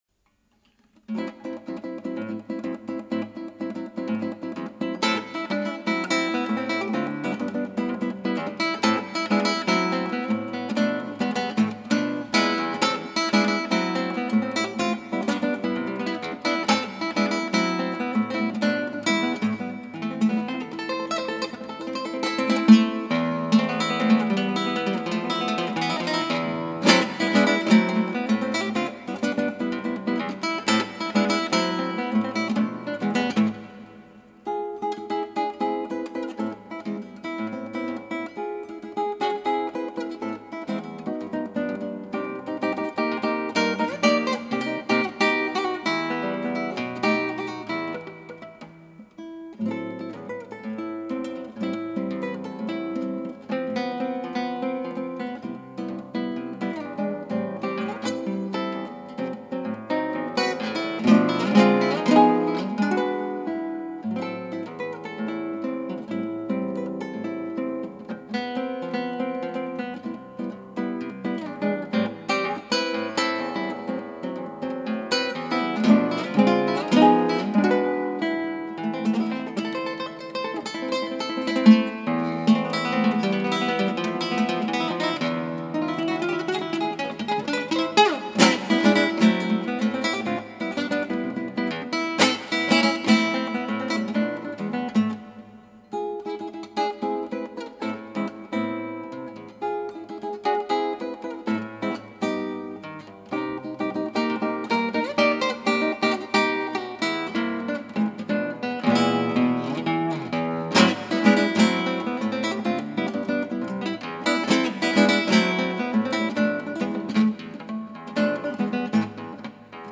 ギターの自演をストリーミングで提供
荒っぽいでつねぇ。